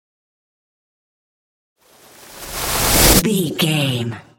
Trailer raiser
Sound Effects
Fast paced
In-crescendo
Atonal
bouncy
driving
futuristic
intense
dramatic
riser